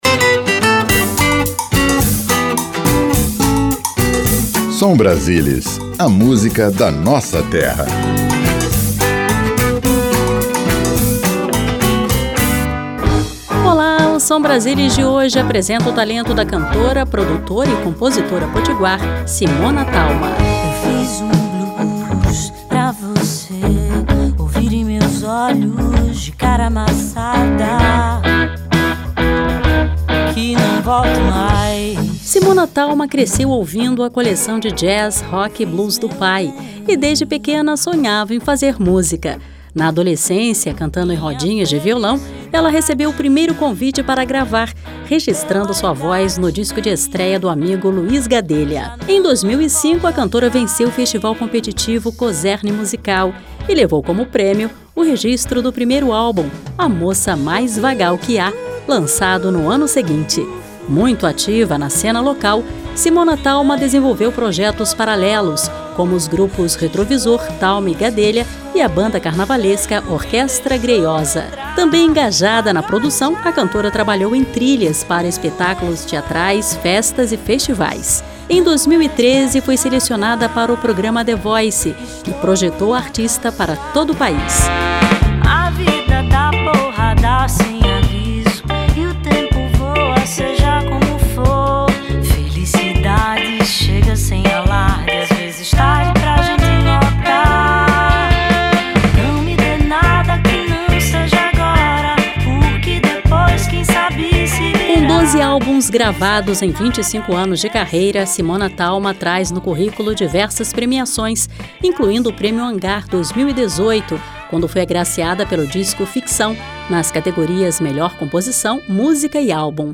Programete musical que apresenta artistas de cada estado da federação.